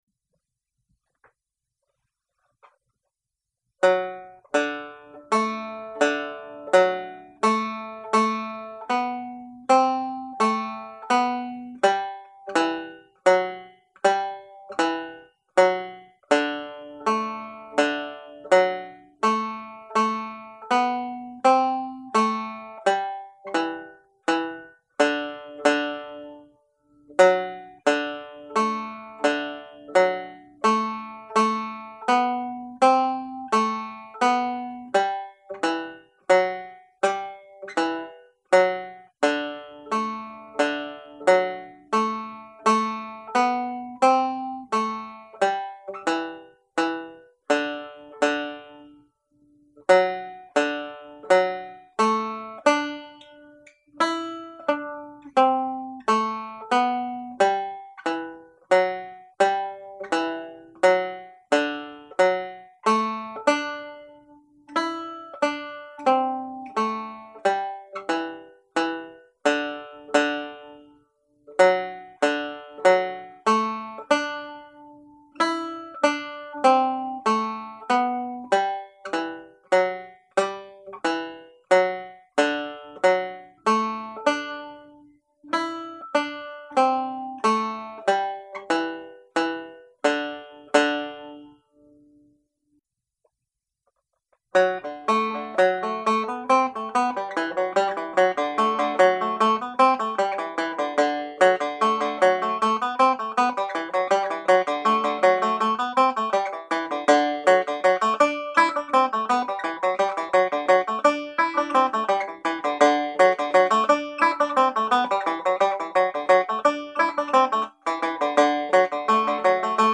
Reel (G Major)